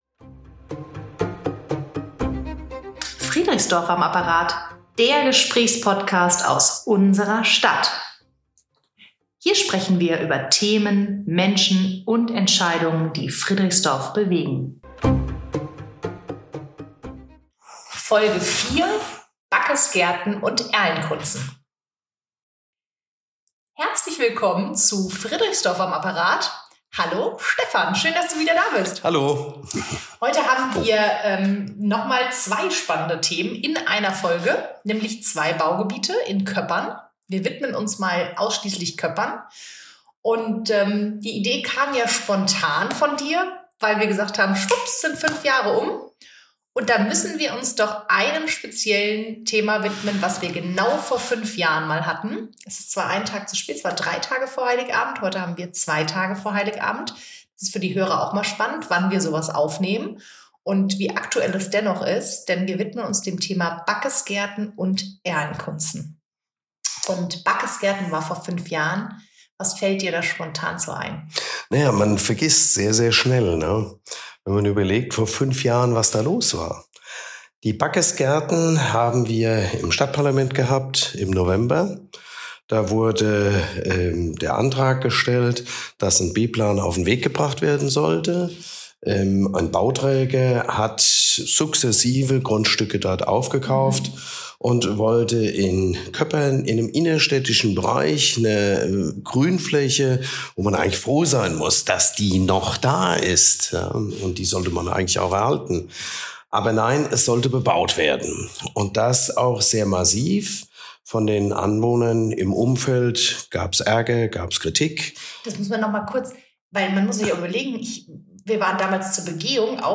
Der Gesprächspodcast aus unserer Stadt